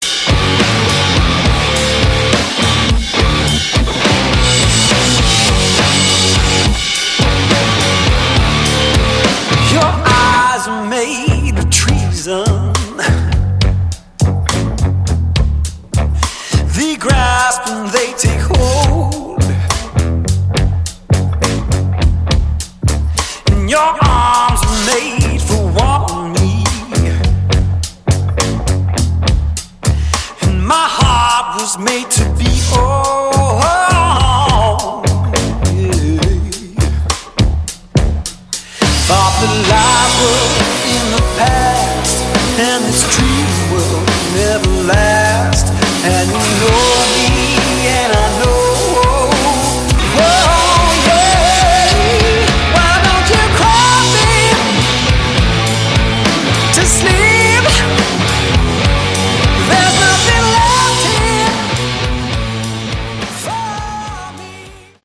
Category: Bluesy Hard Rock
vocals, harmonica
guitar, backing vocals
drums
bass